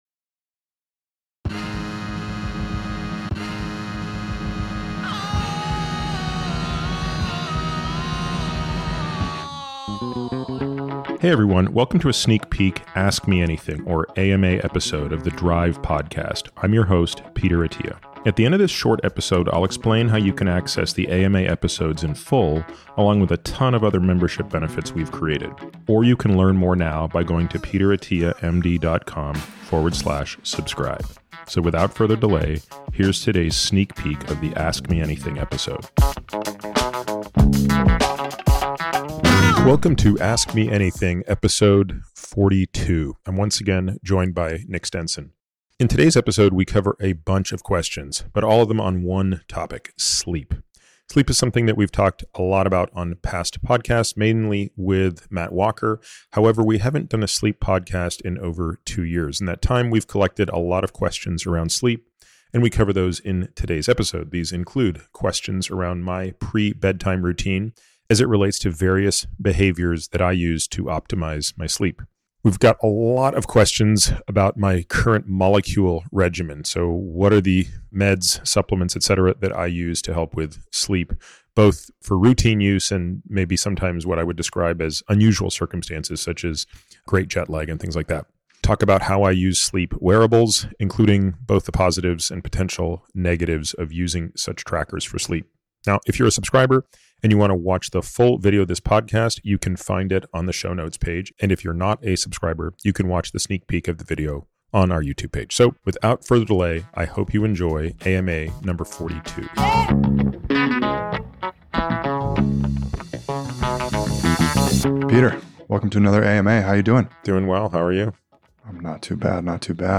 In this “Ask Me Anything” (AMA) episode, Peter answers a number of questions on optimizing sleep. He describes his pre-bedtime routine, how he utilizes a sauna, and his current regimen of medicines and supplements for improving sleep time and quality.